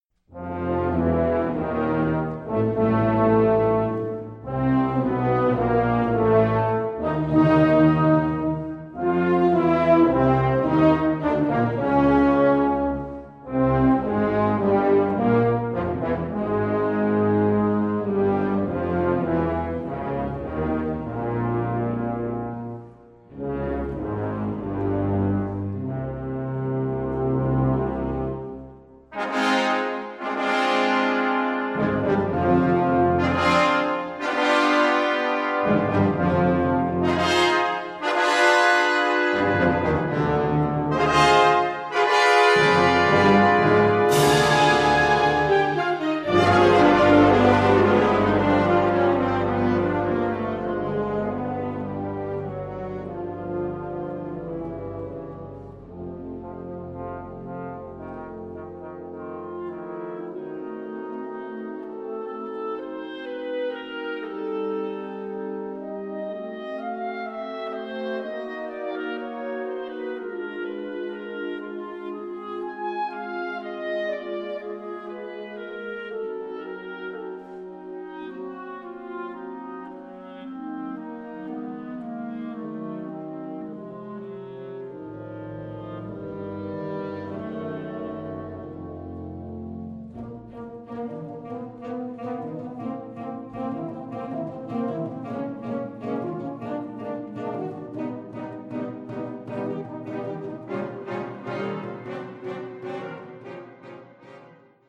Category Concert/wind/brass band
Instrumentation Ha (concert/wind band)
The result was an energetic and colourful work.